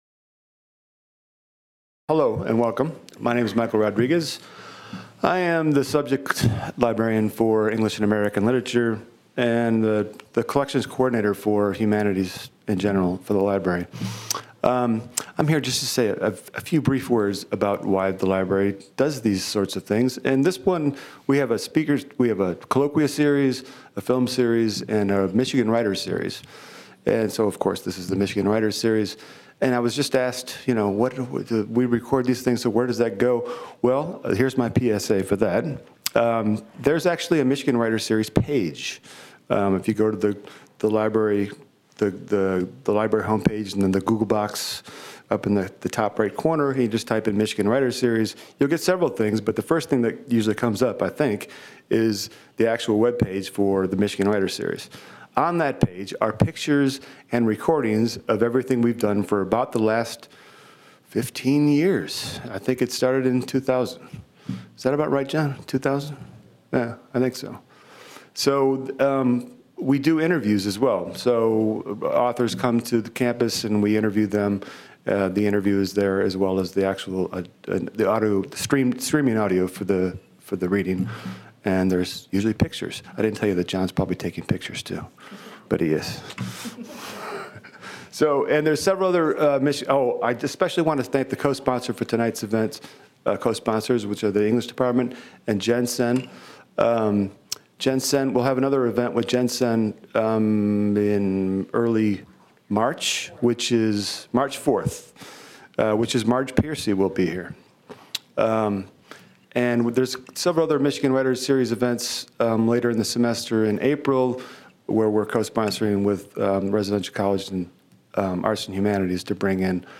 Part of the MSU Libraries' Michigan Writers Series. Held at the MSU Main Library and sponsored by the MSU Department of English and the Center for Gender in Global Context.